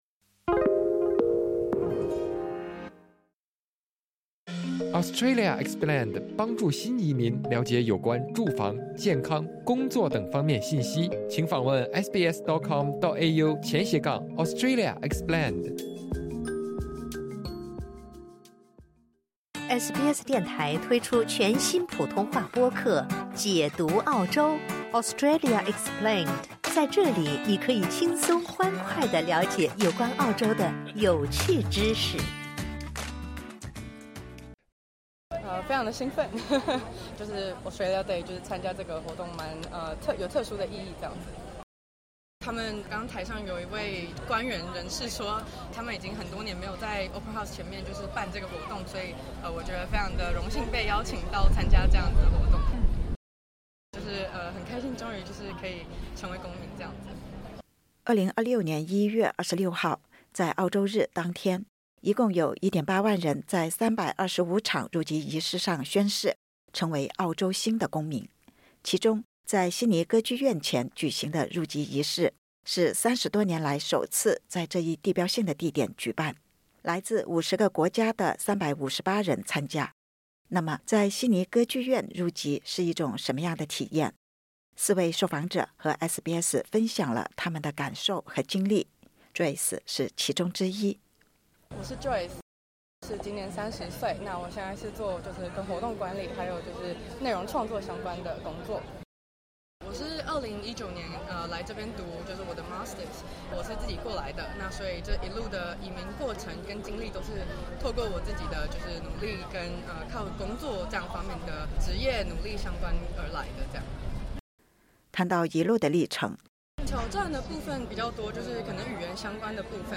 四位受访者和SBS分享了他们的感受和经历。